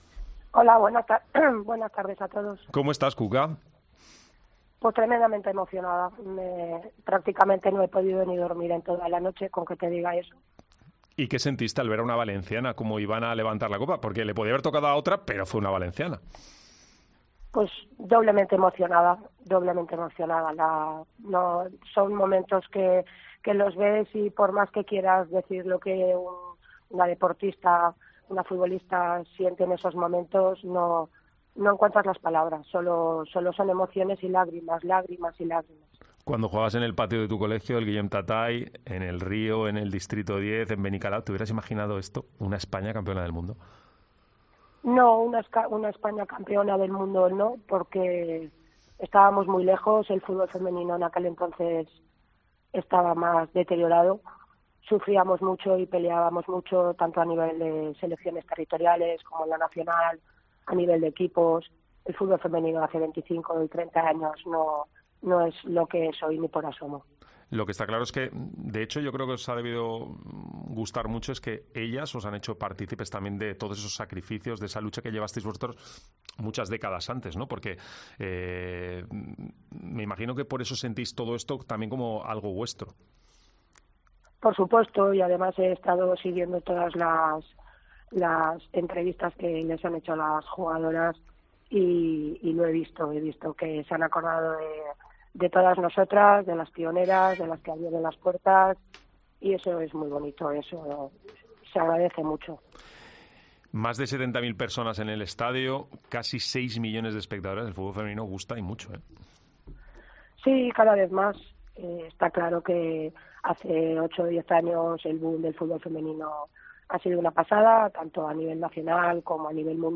Entrevista en COPE con la primera valenciana internacional con España